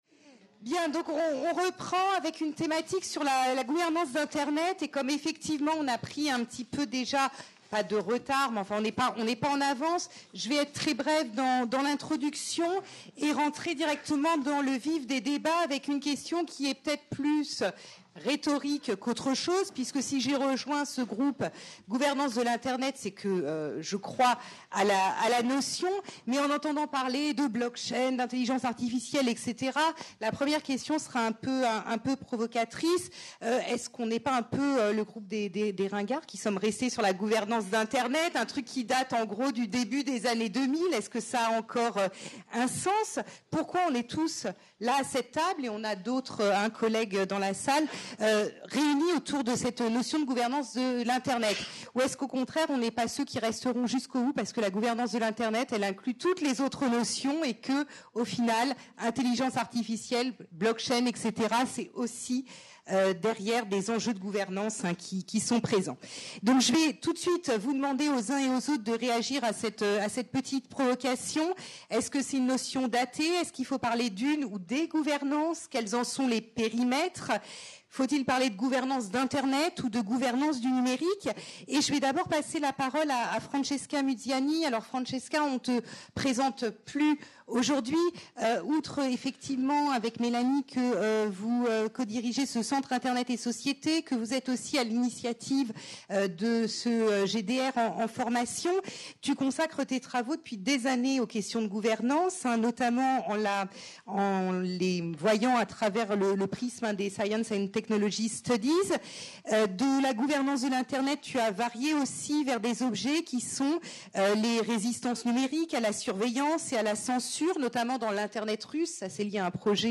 Table ronde : Pourquoi parler de gouvernance d'Internet ?